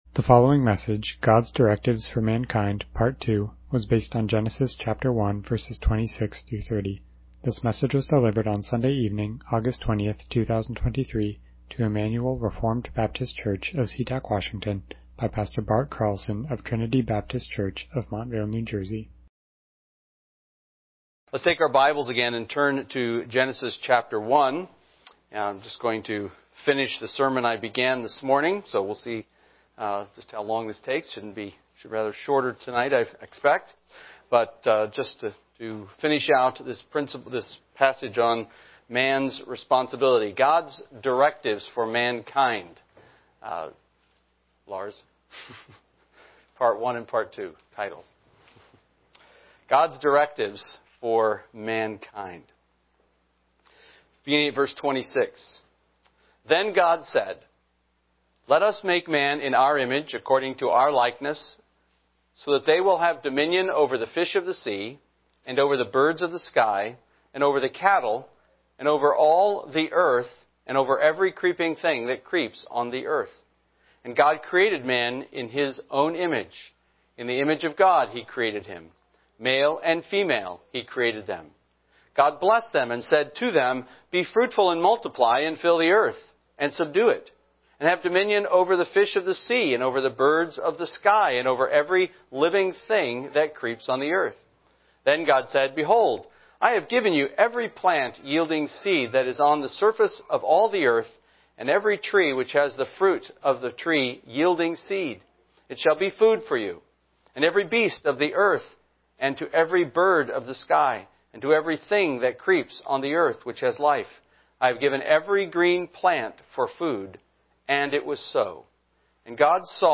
Passage: Genesis 1:26-30 Service Type: Evening Worship « God’s Directives For Mankind